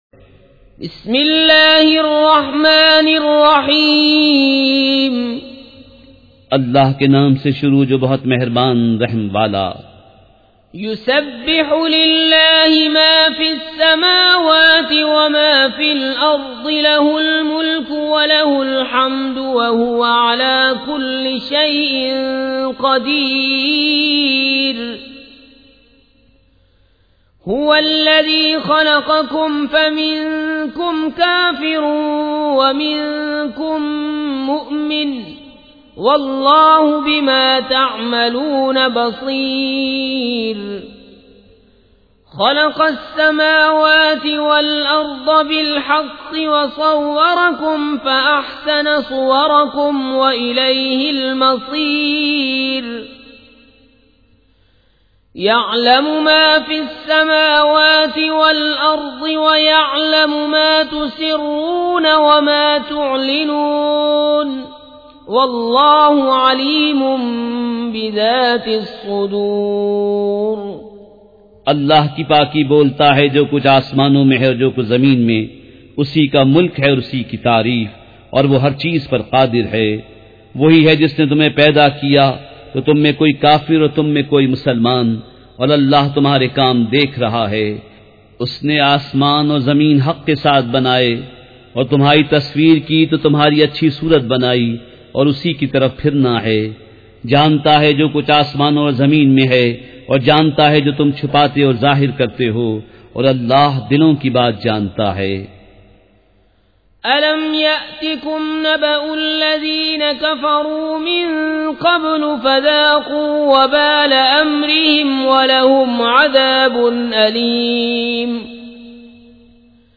سورۃ التغابن مع ترجمہ کنزالایمان ZiaeTaiba Audio میڈیا کی معلومات نام سورۃ التغابن مع ترجمہ کنزالایمان موضوع تلاوت آواز دیگر زبان عربی کل نتائج 1720 قسم آڈیو ڈاؤن لوڈ MP 3 ڈاؤن لوڈ MP 4 متعلقہ تجویزوآراء